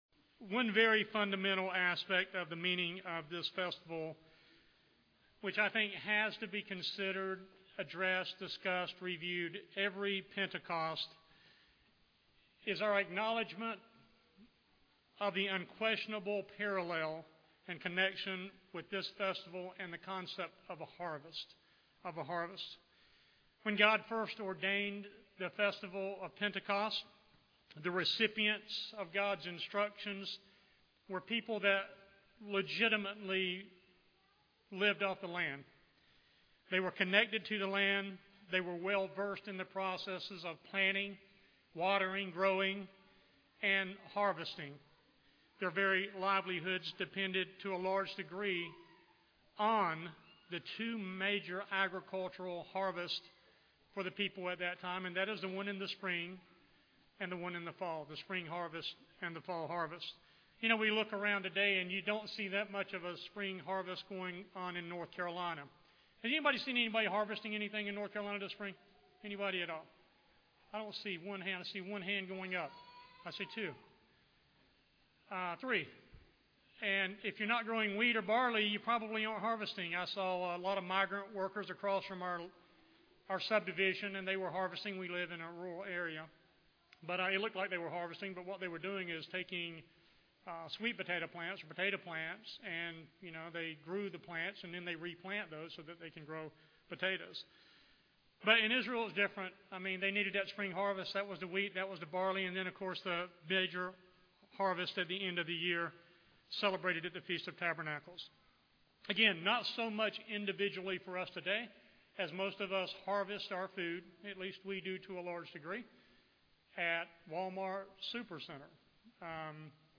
Let us explore the harvest and what it means to all mankind. This message was given on the Feast of Pentecost.